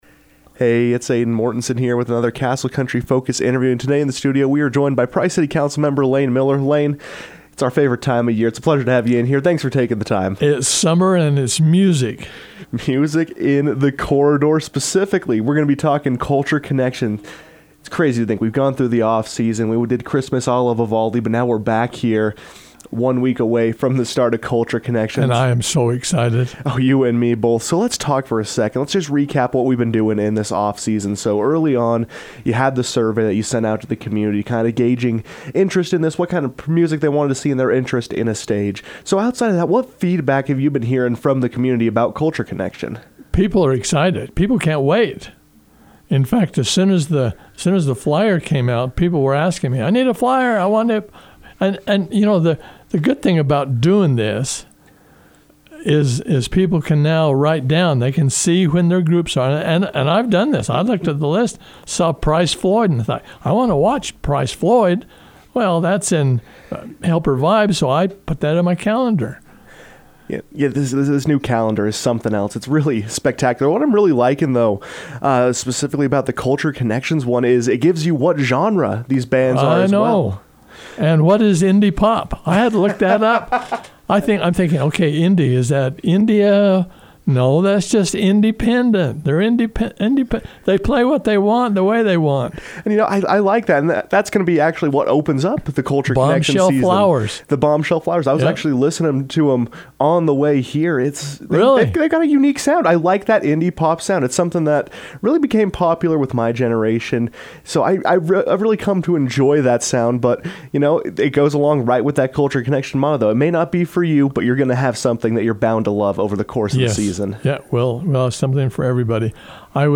With the opening of Price City's Culture Connection series of concerts only a week away, Price City Councilmember Layne Miller joined the KOAL Newsroom to preview the 2025 concert season and speak on the importance of music to the community.